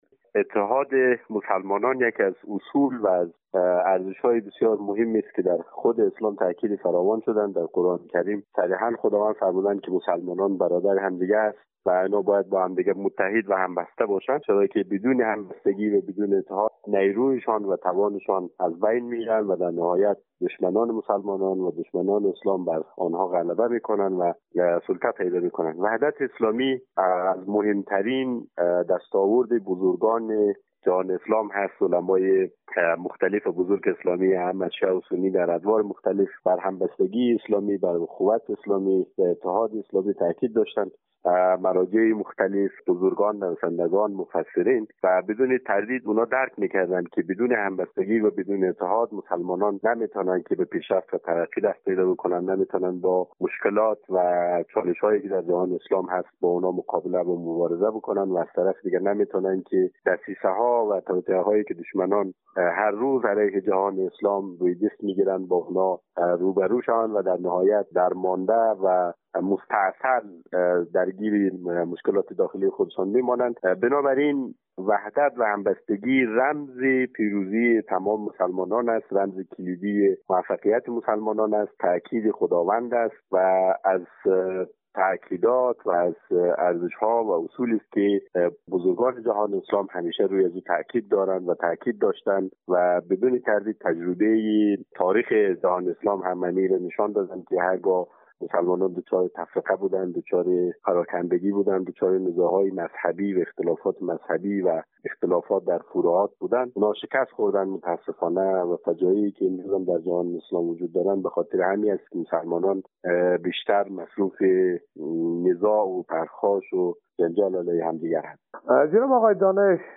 در گفت و گو با خبرنگار رادیو دری در کابل